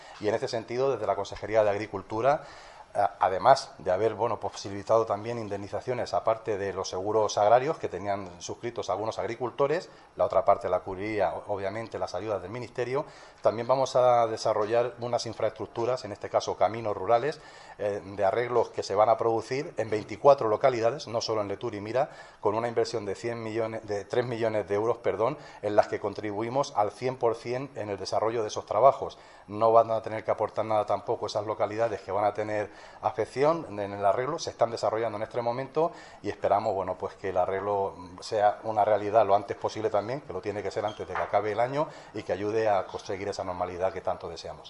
Consejería de Agricultura, Ganadería y Desarrollo Rural Miércoles, 29 Octubre 2025 - 11:45am El consejero de Agricultura, Ganadería y Desarrollo Rural ha explicado que su departamento también está contribuyendo a la reparación de los daños que causó la DANA.